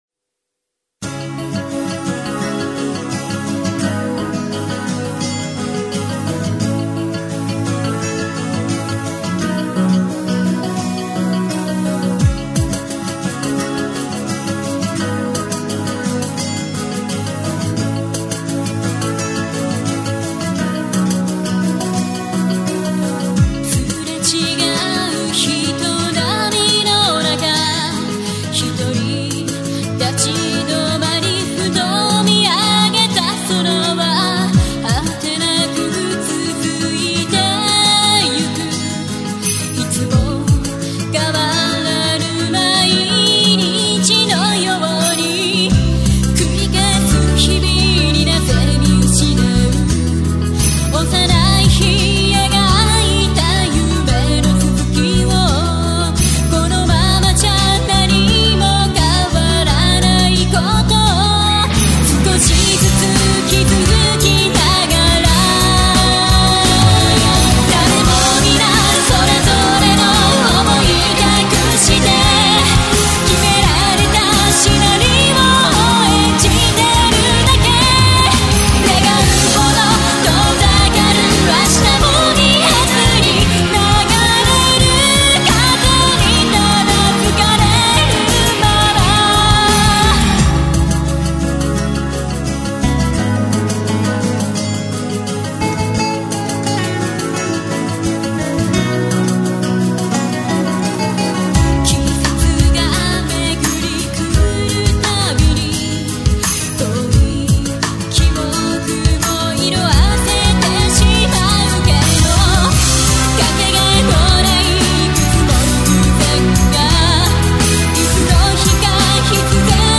synthesizer,program,chorus
vocal